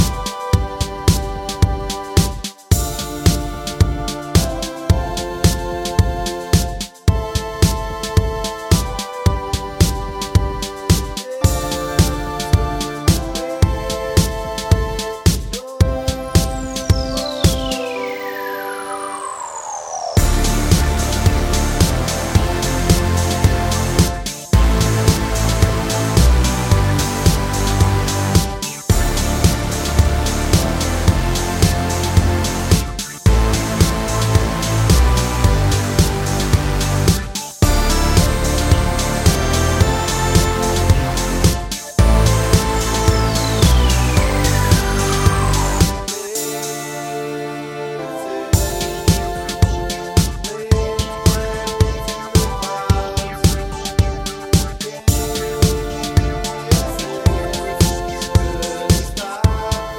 no rap Indie / Alternative 3:50 Buy £1.50